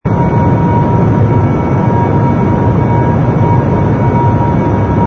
engine_bw_cruise_loop.wav